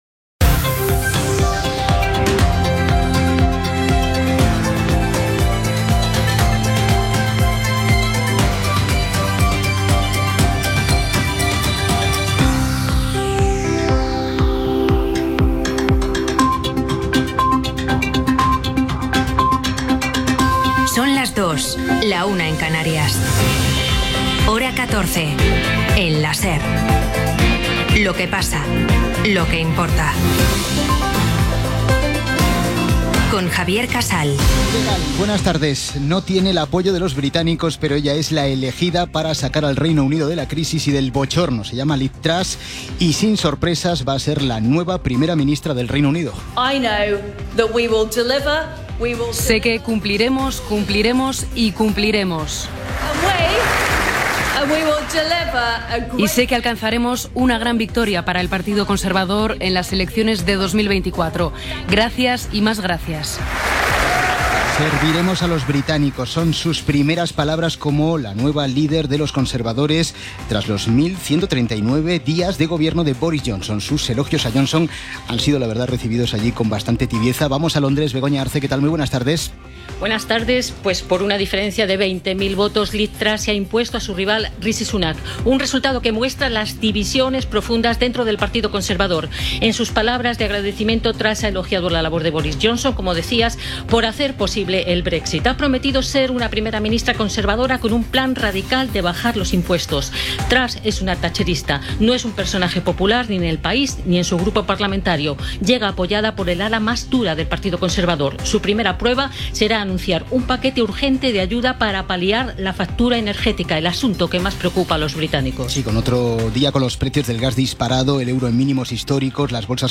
Hora, careta, la nova primera ministra del Regne Unit Lizz Truss, la central nuclear de Zaporíjia (Ucraïna), el preu de la compra domèstica, el debat al Senat espanyol.
Informatiu